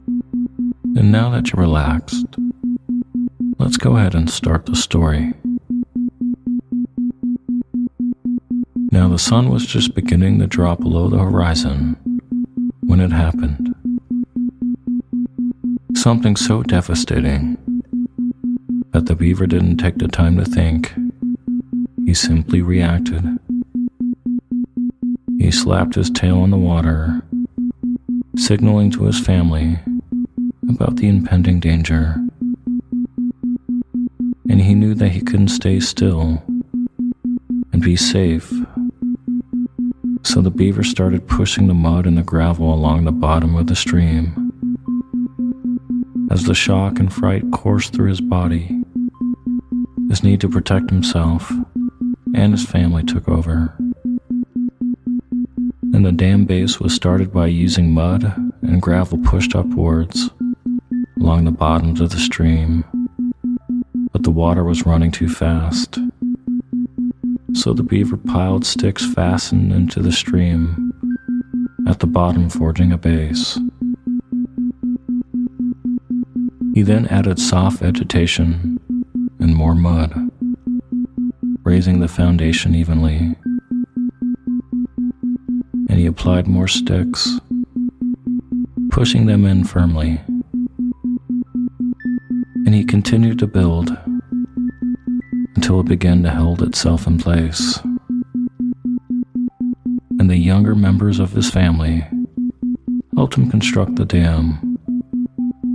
Story Based Meditation "Removing The Dam" With Isochronic Tones